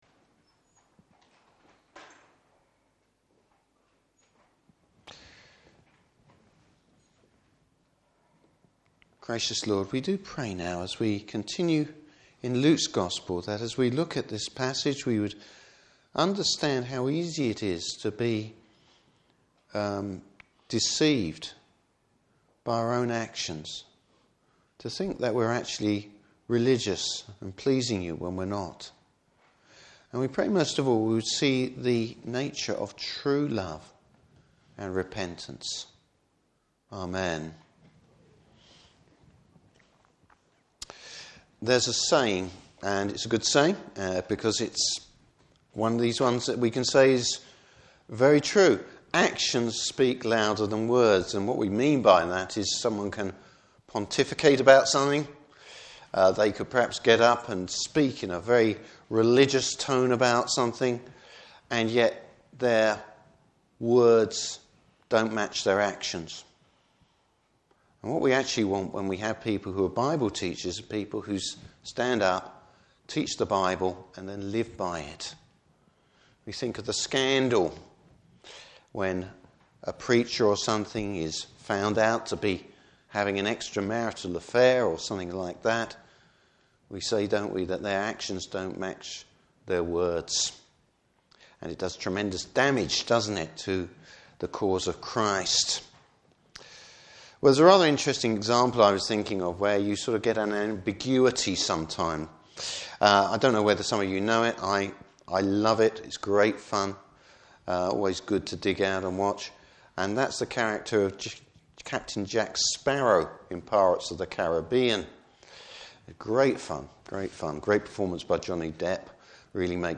Service Type: Morning Service Bible Text: Luke 7:36-8:3.